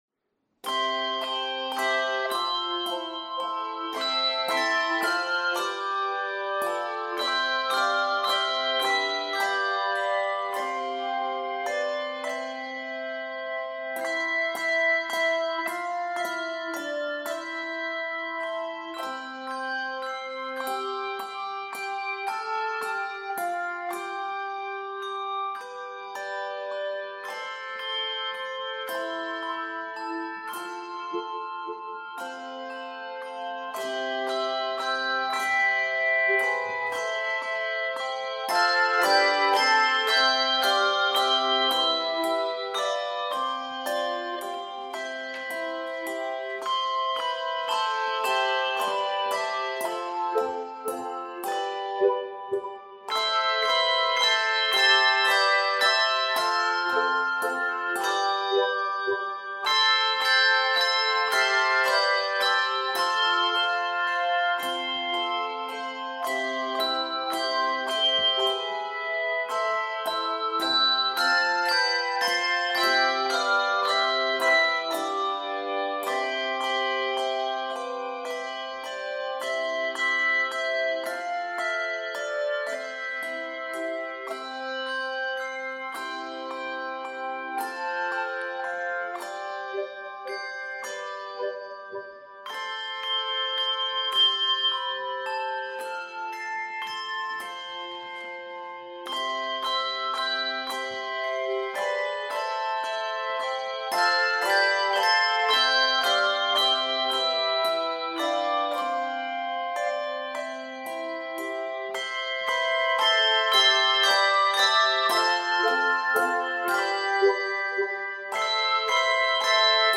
This modern hymn is appropriate for many types of services
Key of C Major.